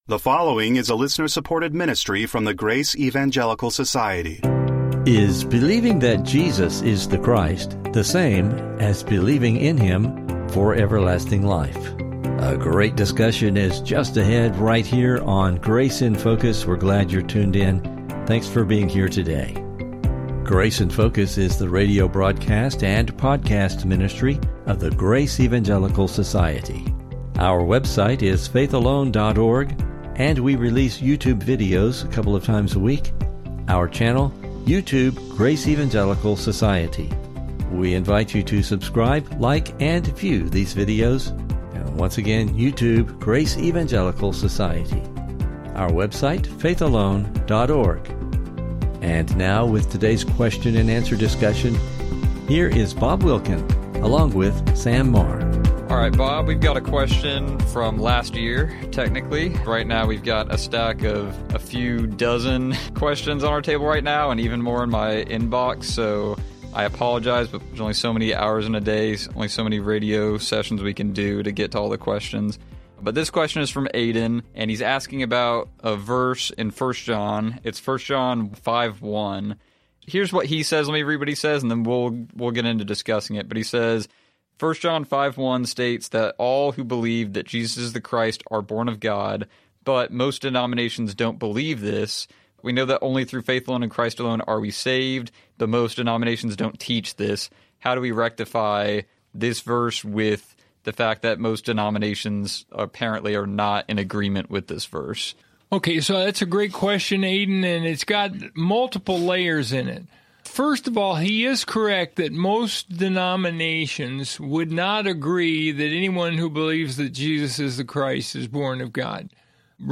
Does belief in Jesus as the Christ (the anointed Messiah) give a person eternal life? What does John mean by “Jesus is the Christ” in 1 John 5:1? Please listen for a challenging discussion and never miss an episode of the Grace in Focus Podcast!